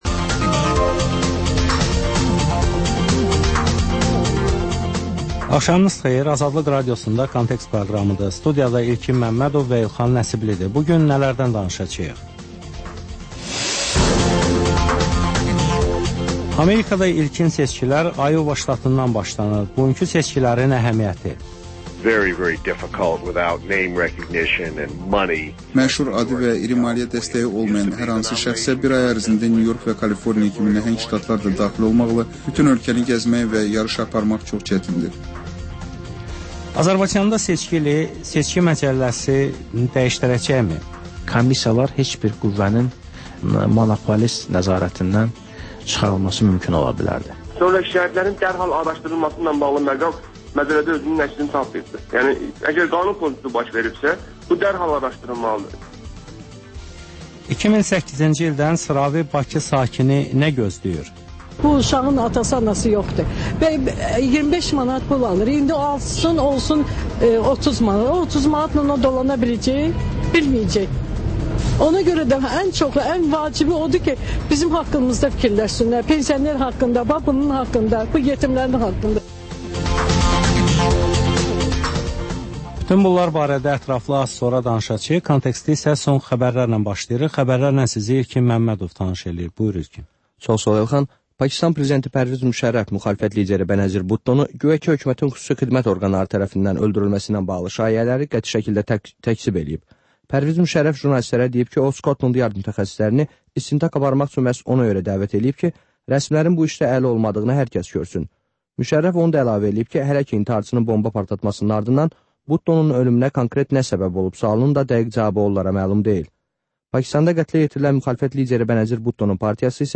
Xəbərlər, müsahibələr, hadisələrin müzakirəsi, təhlillər, sonra QAFQAZ QOVŞAĞI rubrikası: «Azadlıq» Radiosunun Azərbaycan, Ermənistan və Gürcüstan redaksiyalarının müştərək layihəsi